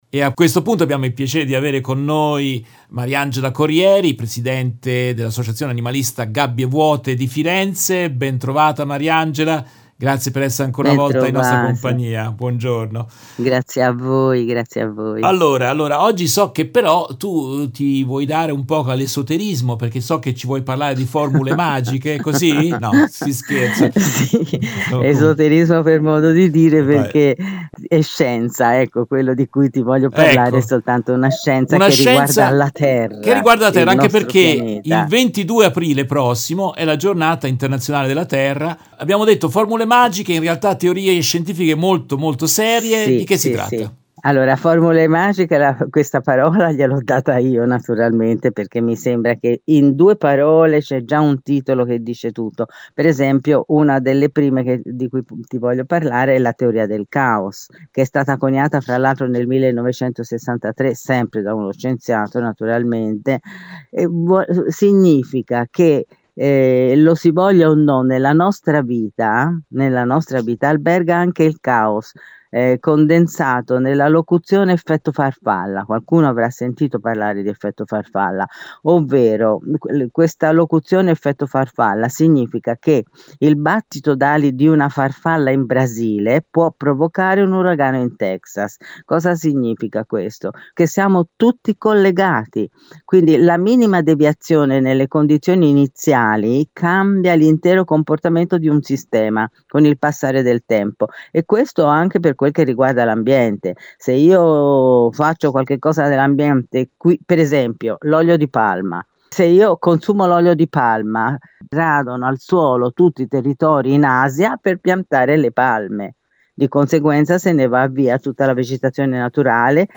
Nel corso della diretta RVS del 5 aprile 2024